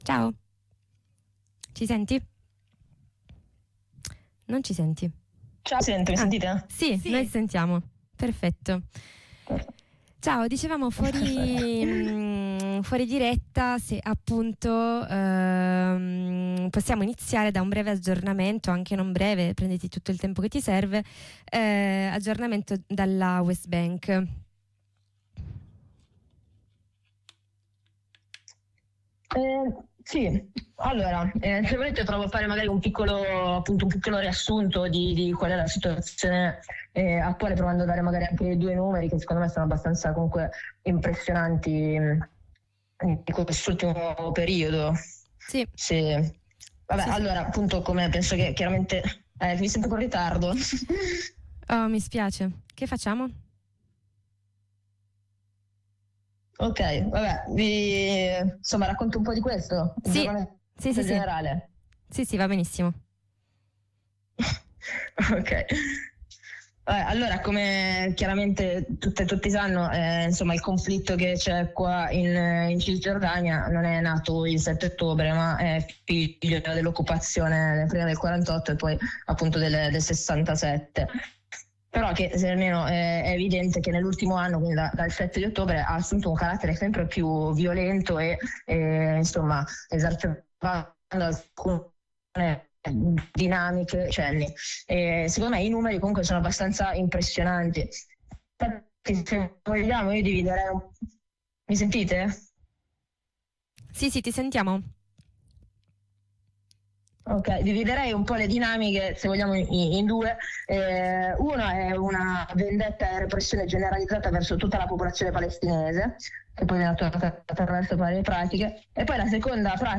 Continuiamo l’approfondimento iniziato qui con la compagna che si trova in una zona C nella Valle del Giordano, in Cisgiordania.